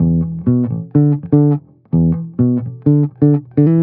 20 Bass Loop E.wav